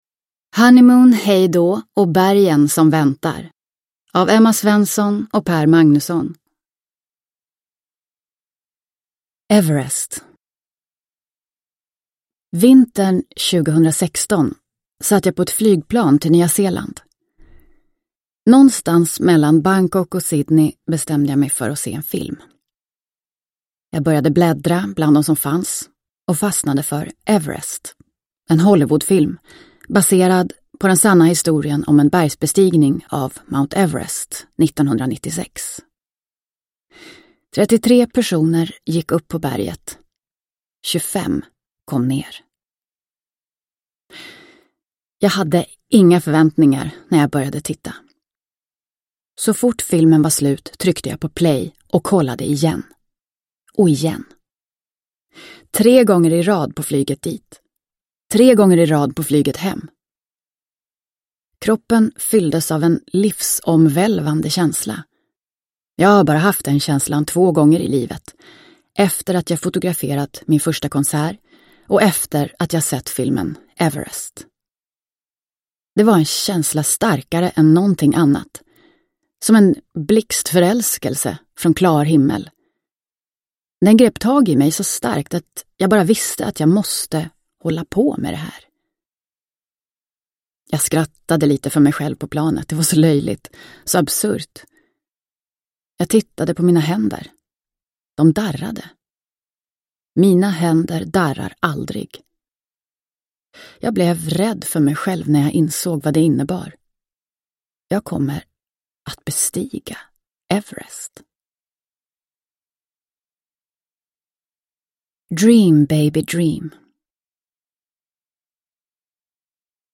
Honeymoon, hejdå & bergen som väntar – Ljudbok – Laddas ner